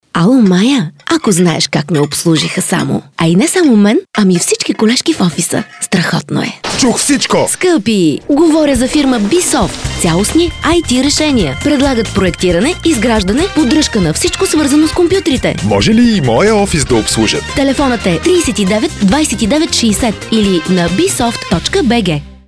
Радио Реклама
BiSoftRadioPublicity.mp3